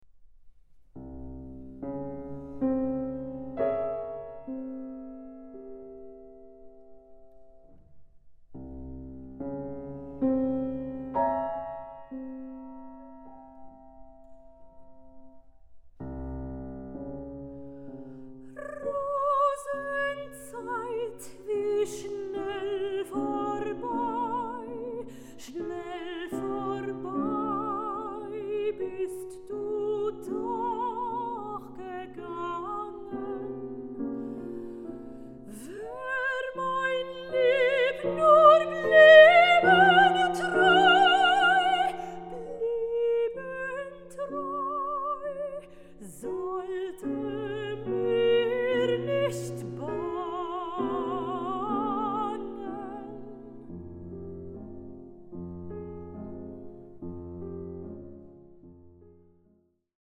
Recording: Alfried-Krupp-Saal, Philharmonie Essen, 2024